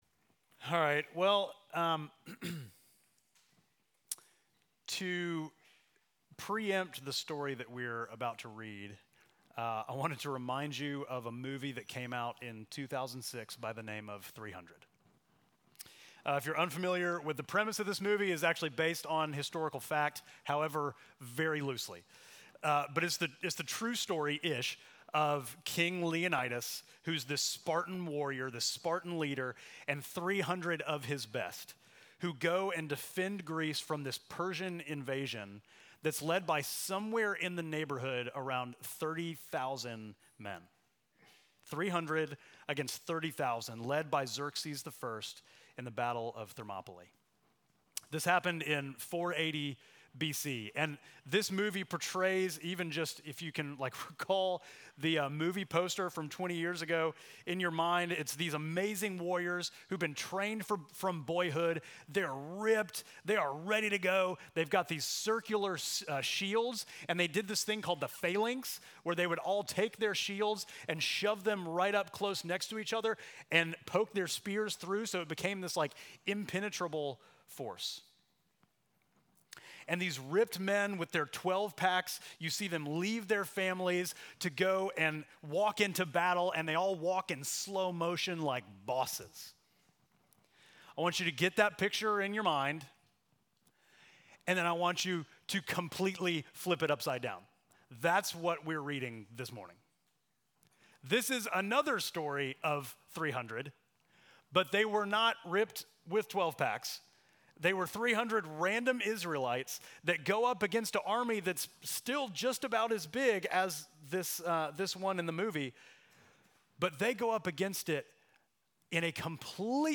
Midtown Fellowship Crieve Hall Sermons Gideon: The Weak Savior Oct 20 2024 | 00:35:33 1x 00:00 / 00:35:33 Subscribe Share Apple Podcasts Spotify Overcast RSS Feed Share Link Embed